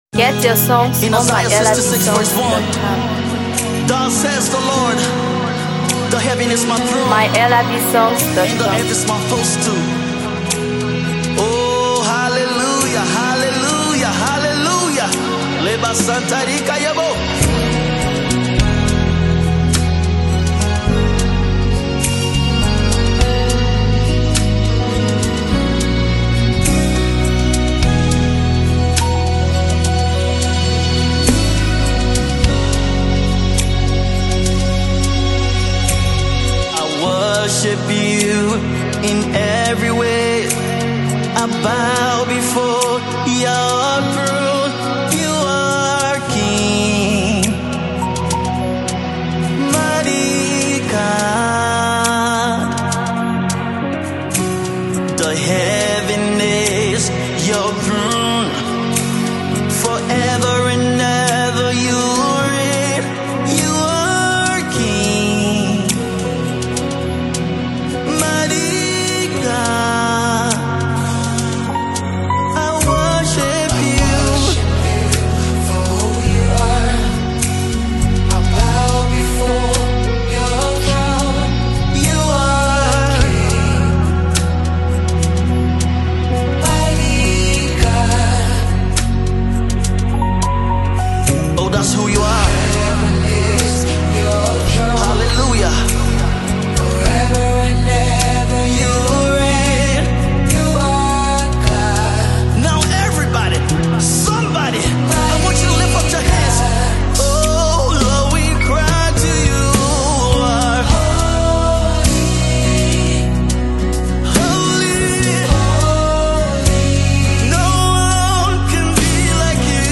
GospelMusic
spirit-filled banger
soulful voice